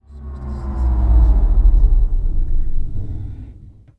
VEC3 Reverse FX
VEC3 FX Reverse 33.wav